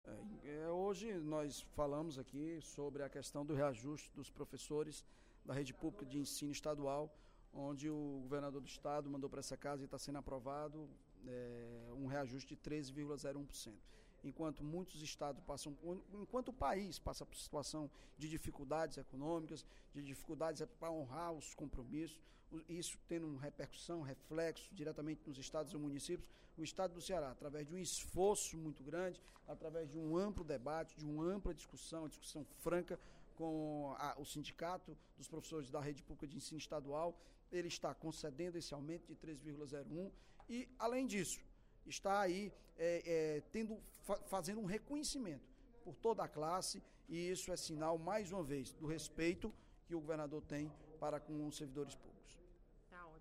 O líder do Governo na Assembleia Legislativa, deputado Evandro Leitão (PDT), destacou, durante o primeiro expediente da sessão plenária desta sexta-feira (19/06), a mensagem nº 7.741, de autoria do Poder Executivo, votada hoje em plenário.